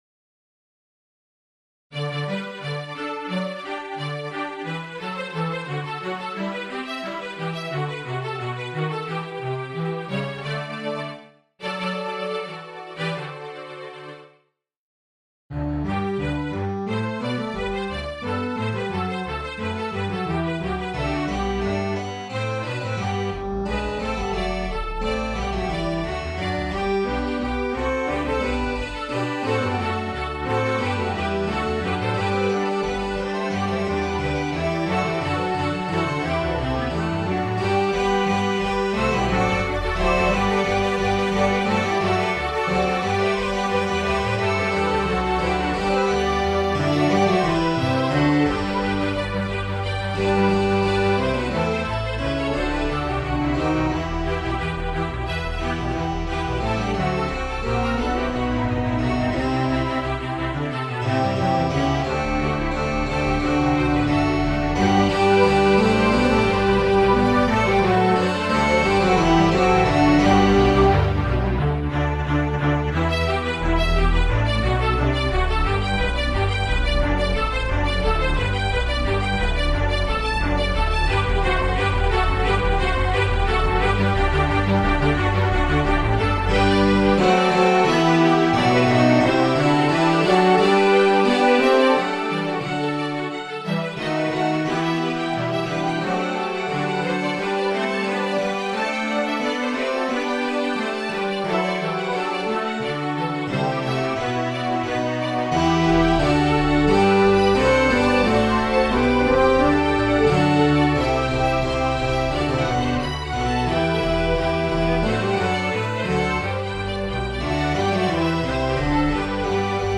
Chant de Noël
Tonality: G major